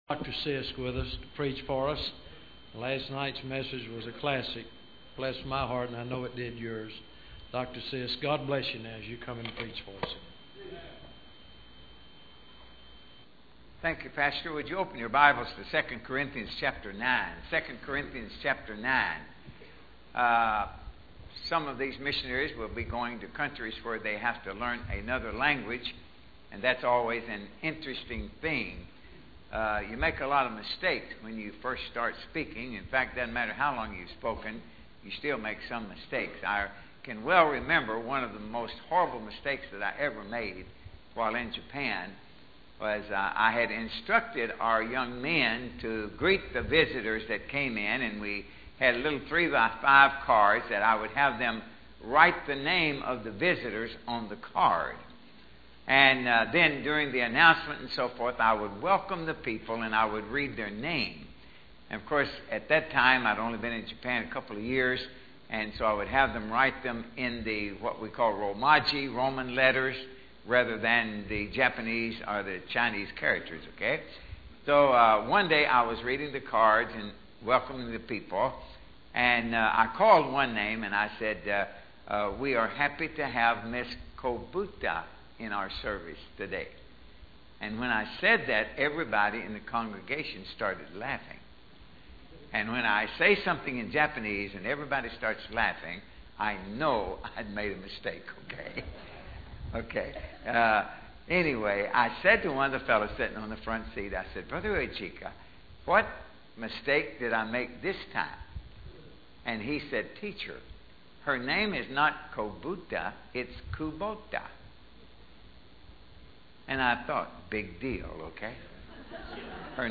Mission Conference 2013